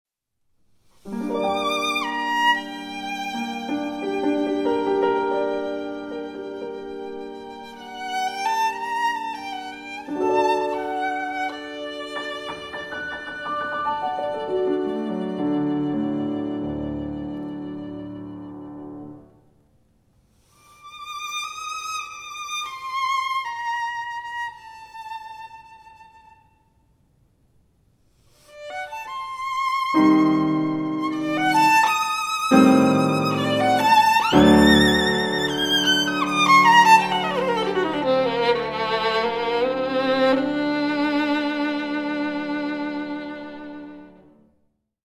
for violin and piano
violin
piano